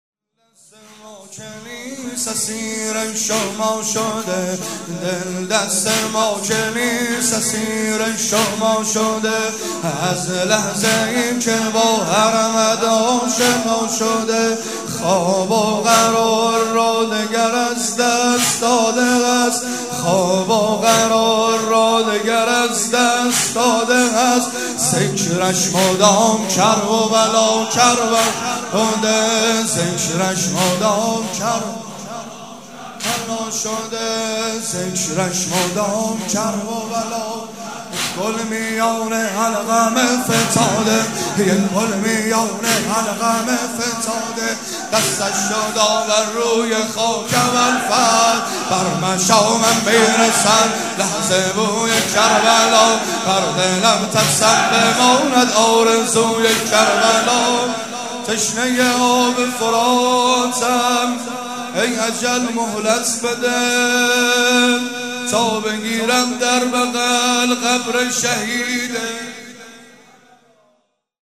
مداح
مراسم عزاداری شب ‌پنجم